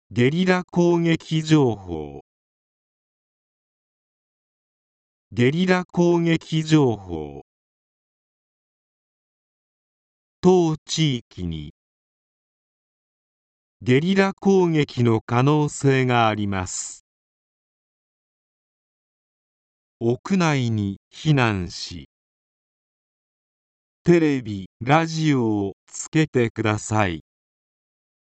市内136か所に設置したスピーカーを通して、災害予防や災害対策などの情報を一斉に、より早く市民の皆さんに伝達することができるようになりました。